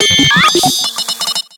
Cri de Porygon-Z dans Pokémon X et Y.